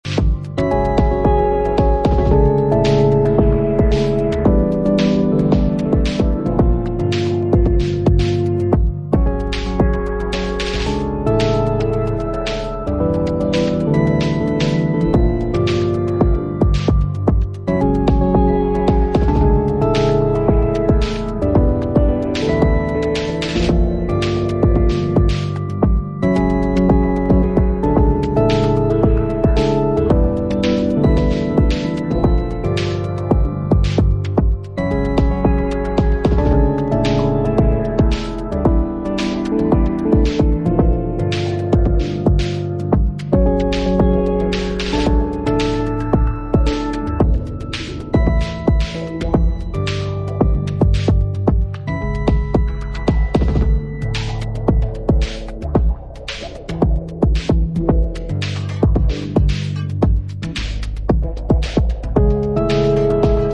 Electro Electronix